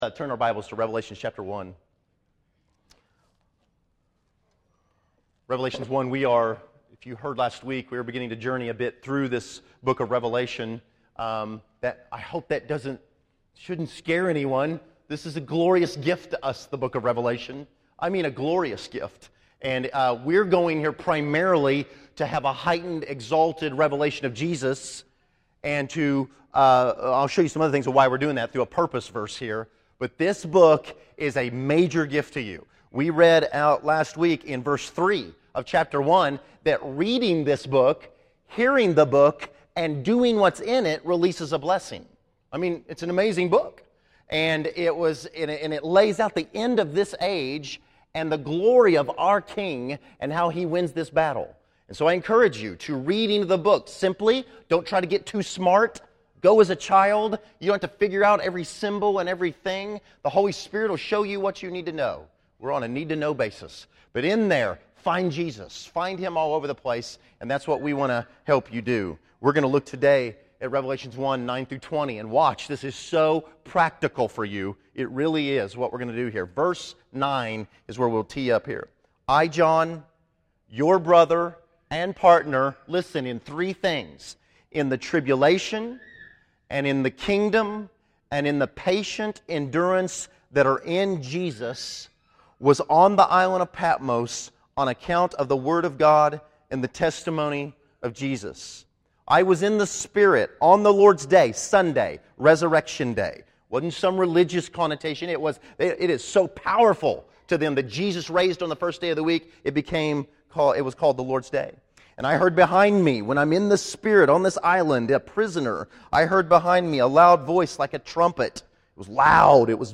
Category: Sermons | Location: El Dorado